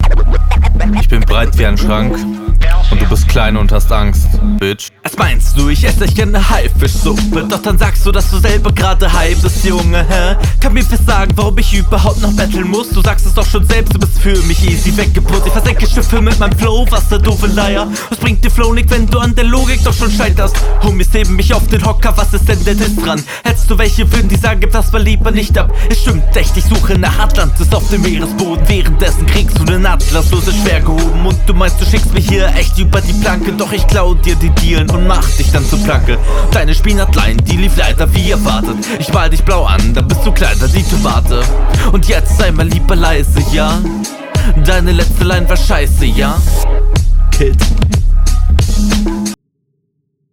Flow kommt recht chillig, der Beat ist aber diesmal auch nicht ABSOLUTES ABWASSER.